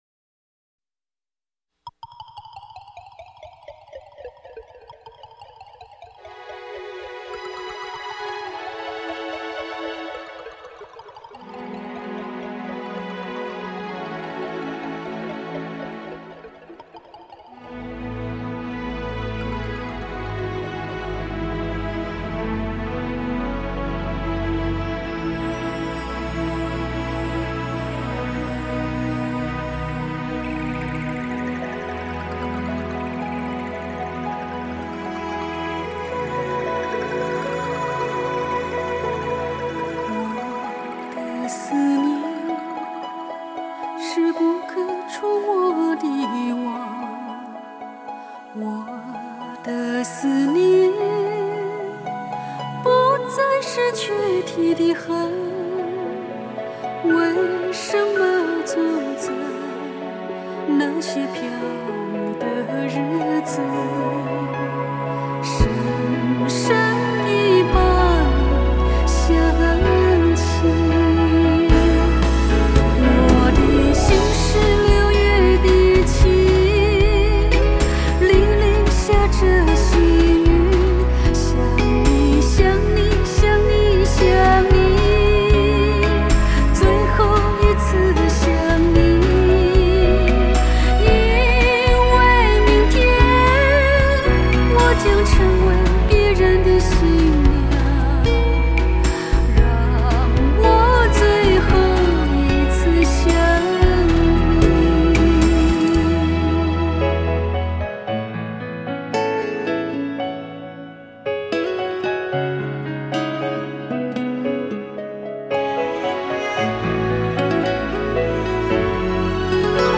经典情歌15首·63分钟超值大碟